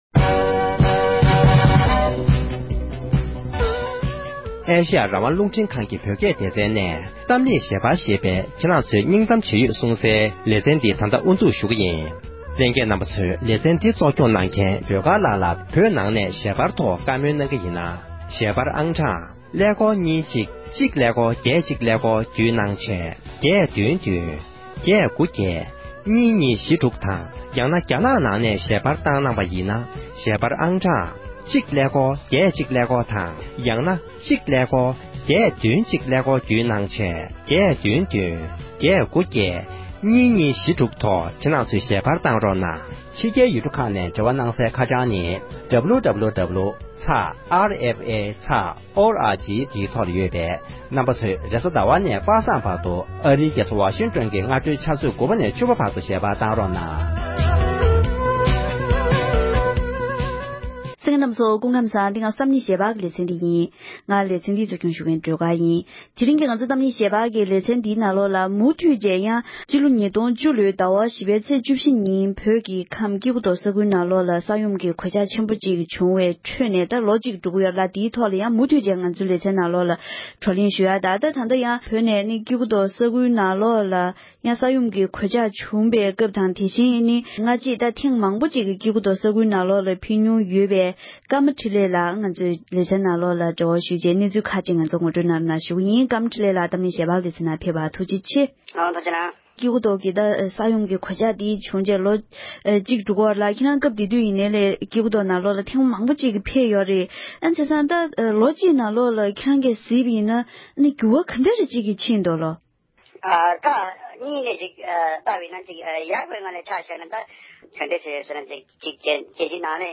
སྐྱེ་རྒུ་མདོར་ས་ཡོམ་གྱི་གནོད་འཚེ་བྱུང་བའི་རྗེས་དང་བླངས་ལས་དོན་སྤེལ་པར་ཕེབས་མཁན་ཞིག་དང་གླེང་མོལ།